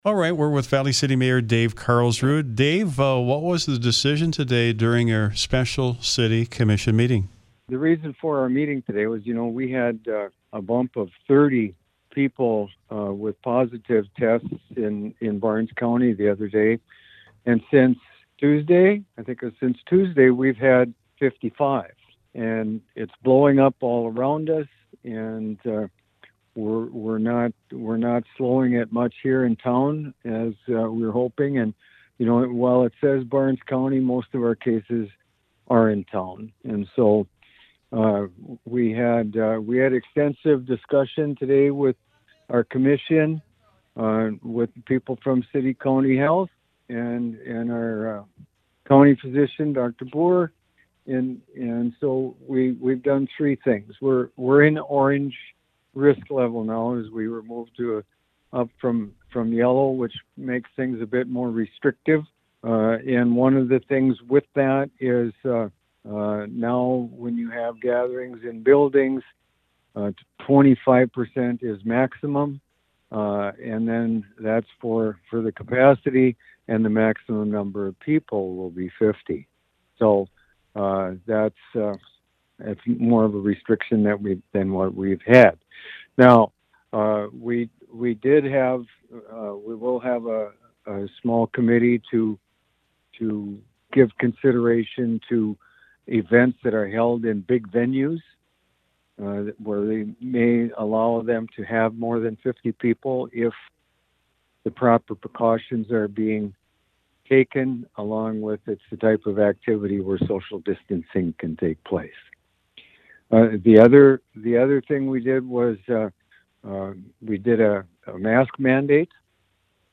The full interview with the mayor is available below.